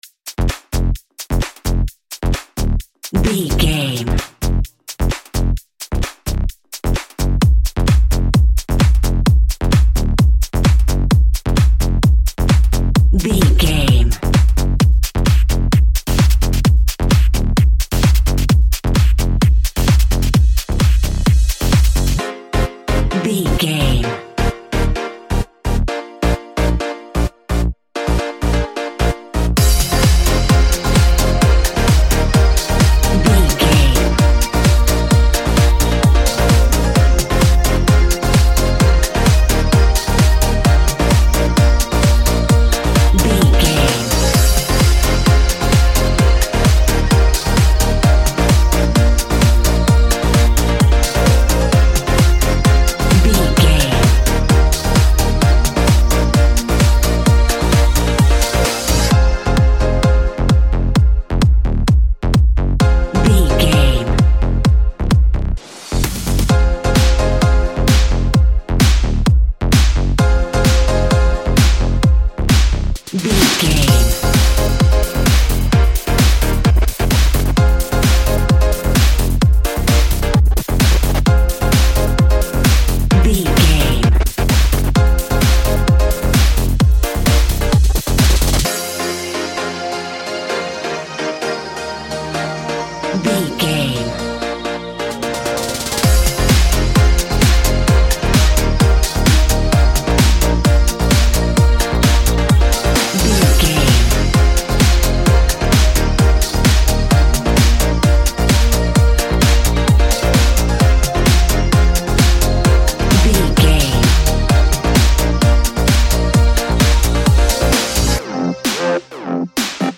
Epic / Action
Fast paced
Aeolian/Minor
Fast
dark
futuristic
groovy
aggressive
energetic
driving
electric piano
synthesiser
drum machine
house
electro dance
techno
trance
instrumentals
synth leads
synth bass
upbeat